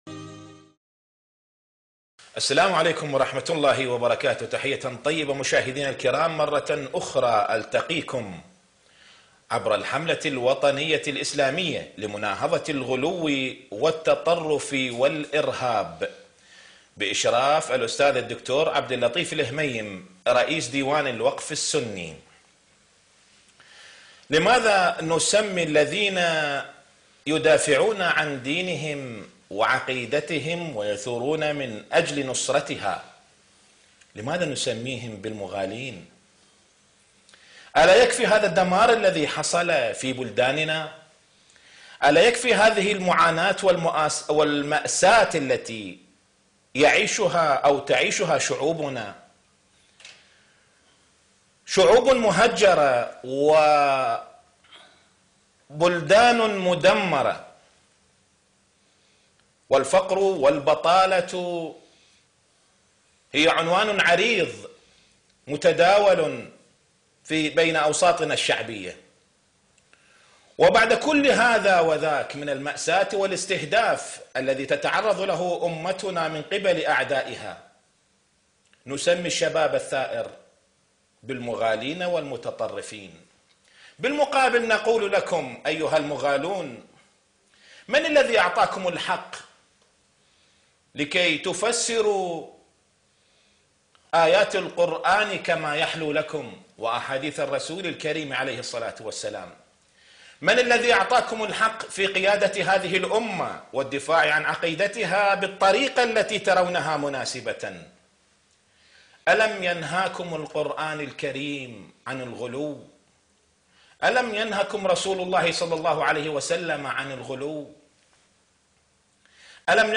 الوسطية - لقاء خاص - الشيخ محمد راتب النابلسي